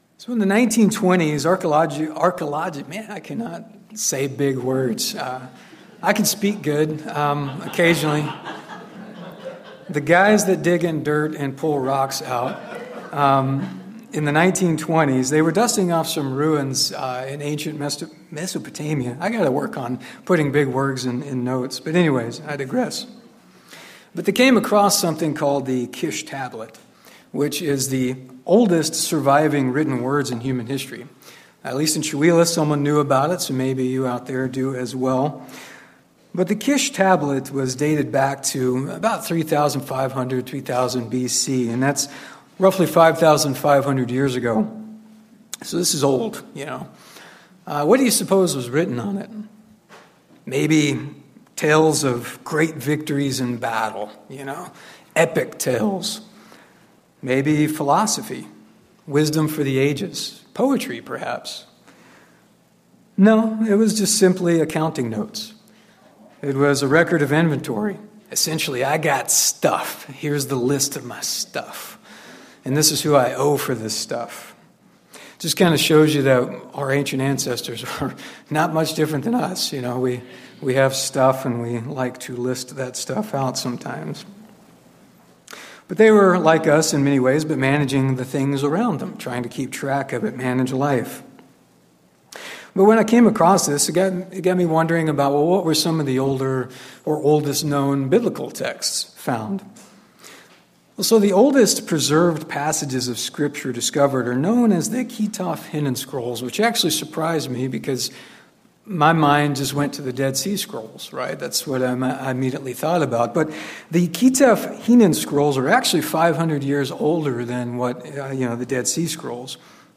In Part 1 of this 2 sermon series we’ll look at the first half of that verse.